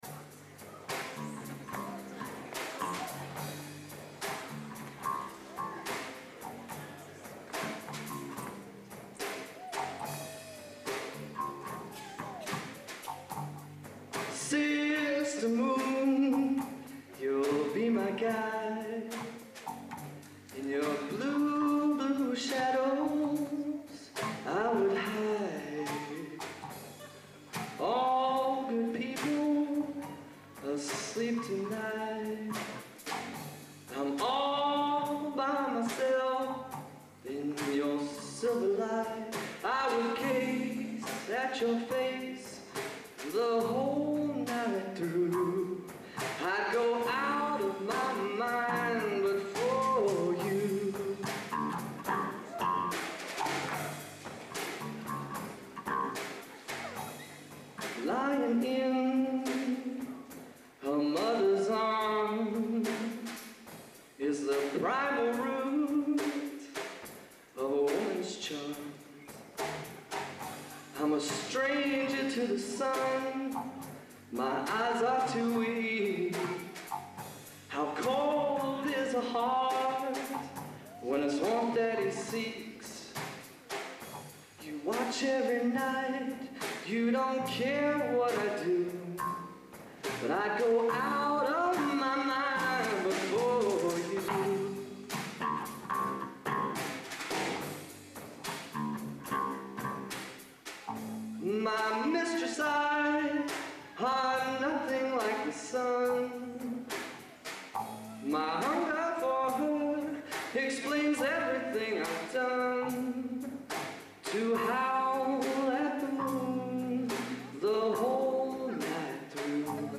Location: Purdue Memorial Union, West Lafayette, Indiana
Genre: | Type: Solo